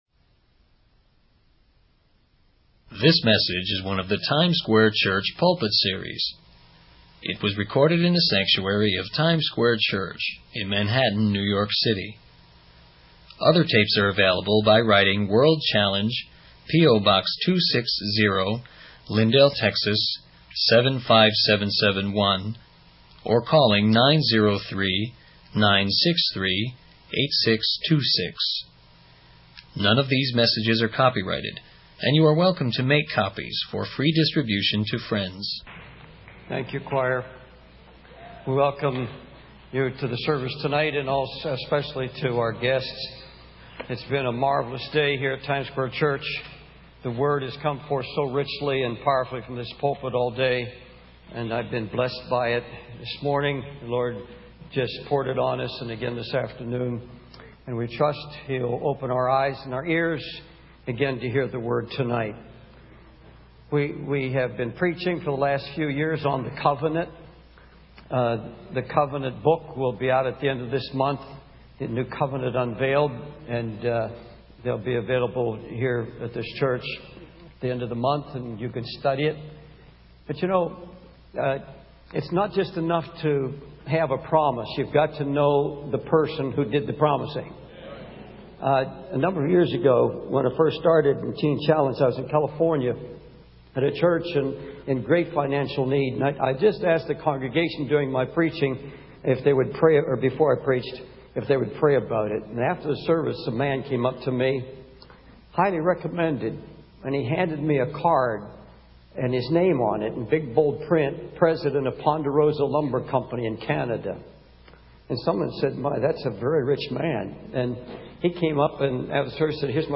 It was recorded in the Sanctuary of Times Square Church in Manhattan, New York City.